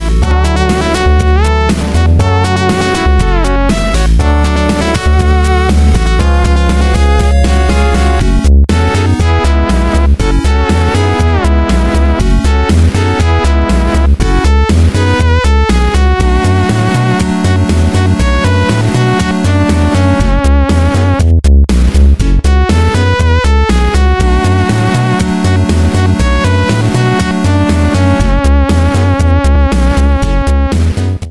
• Качество: 192, Stereo
электронная музыка
Electronica
8-бит
Известная песня в электронной обработке из игры для Сеги